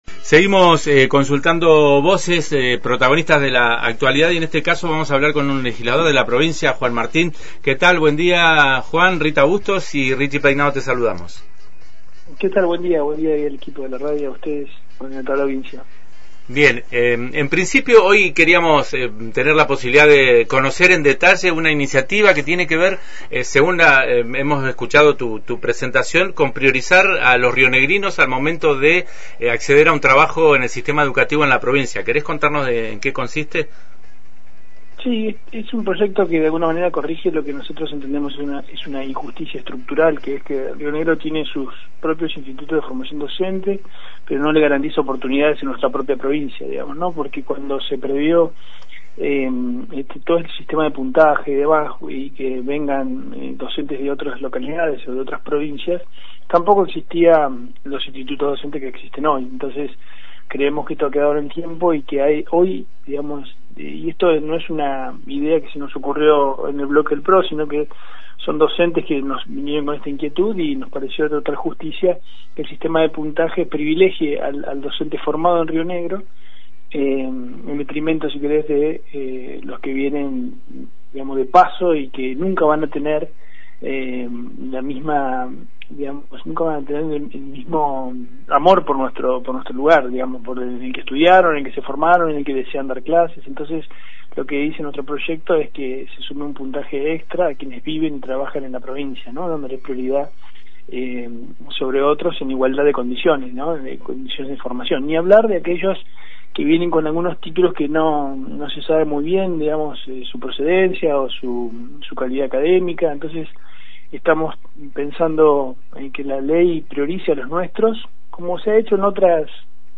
Según explicó en Antena Libre, la propuesta surgió a partir de planteos de docentes y podría complementarse con un proyecto similar presentado por la UCR.